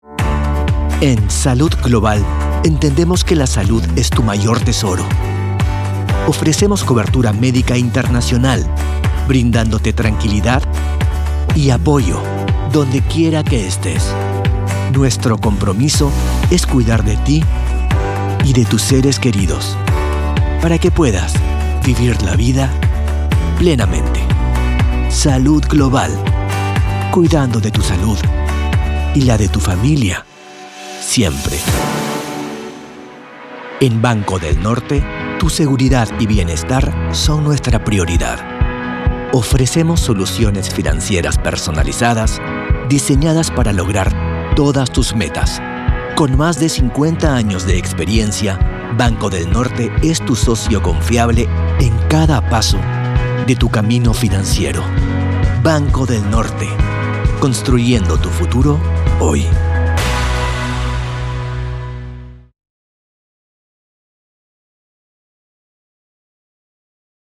Espanhol (latino-americano)
Confiante
Detalhado
Idoso